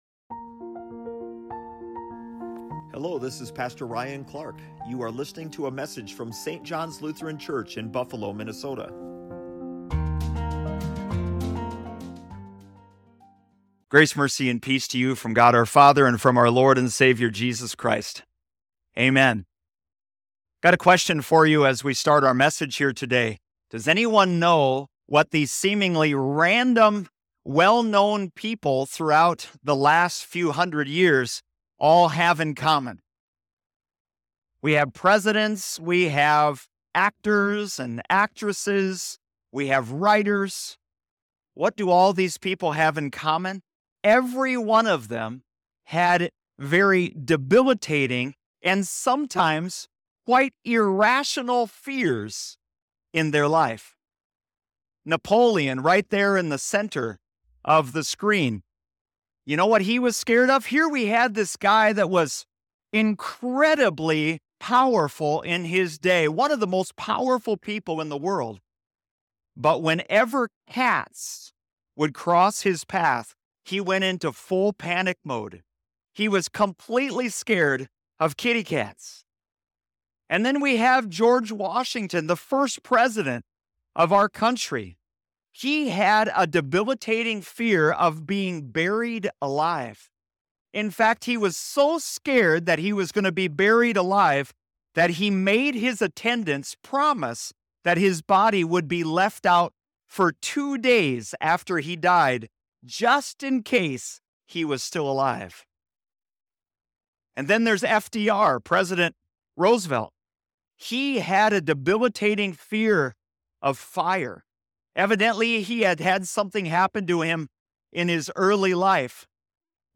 Get answers in week 5 of the sermon series "I Met Him!"